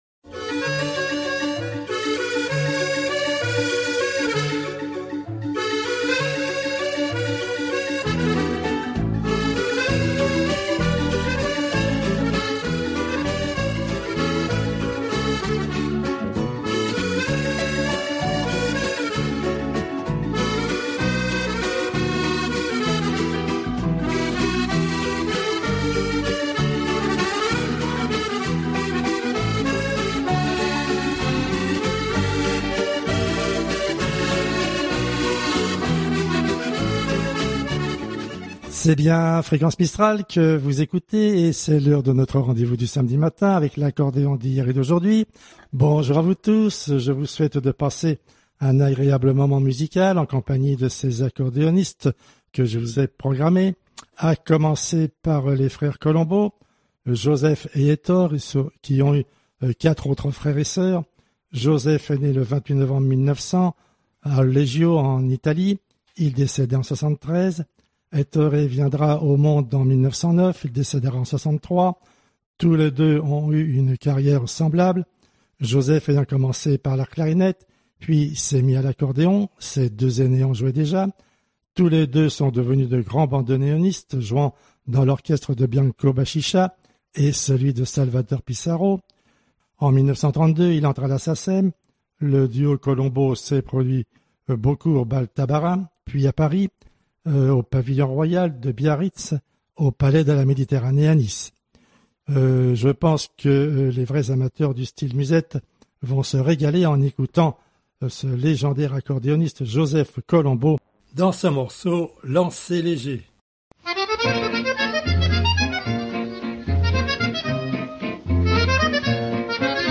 Accordéoniste anonyme suédois